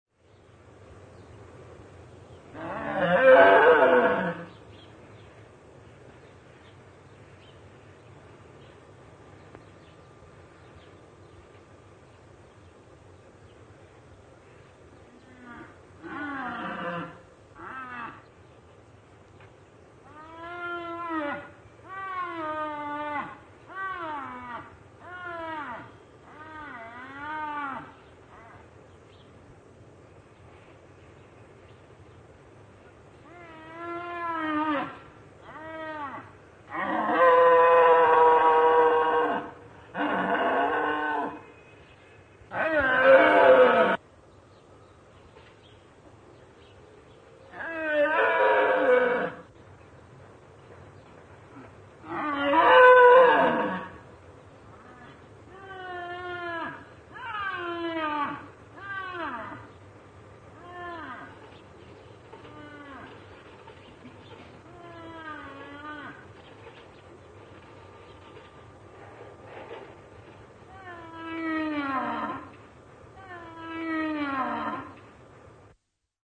Звук взрослого животного из зоопарка